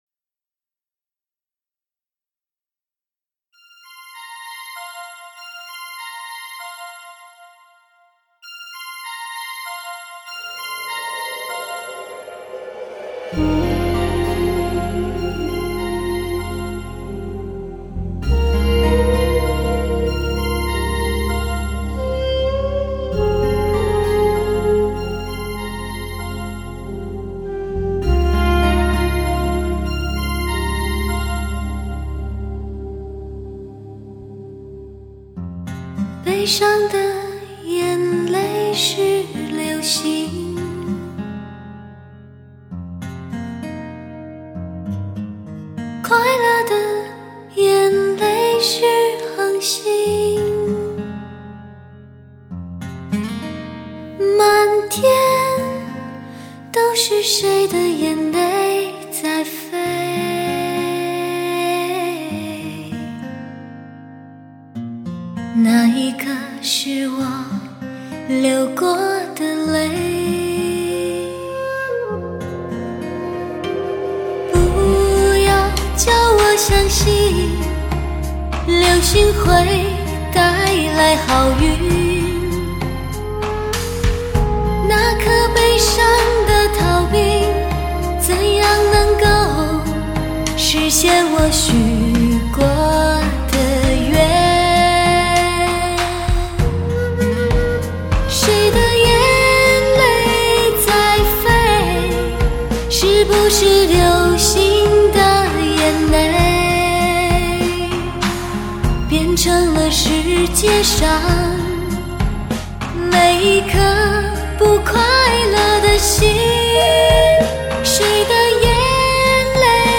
专为名车音响系统研制的黑胶测试天碟！
至尊奢华黑胶天碟，共从多张发烧极品中淬选15首天籁女声；